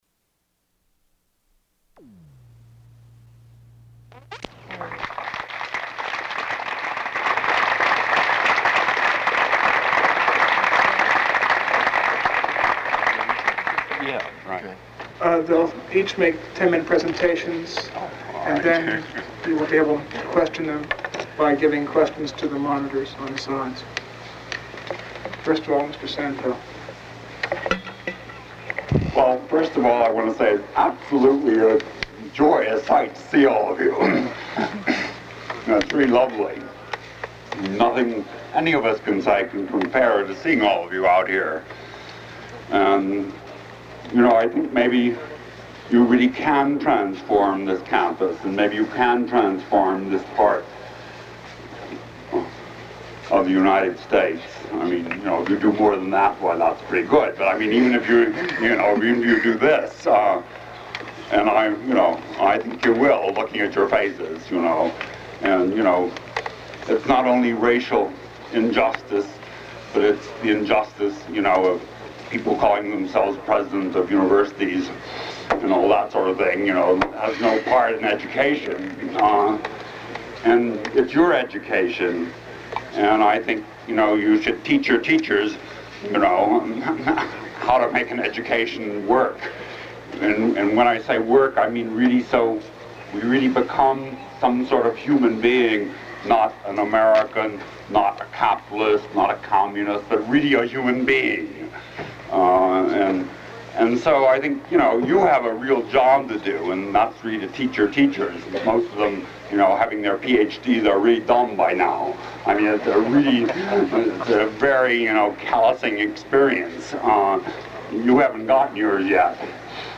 April 8, 1968 rally on the main quad / Silent Vigil (1968) and Allen Building Takeover (1969) Audio Recordings / Duke Digital Repository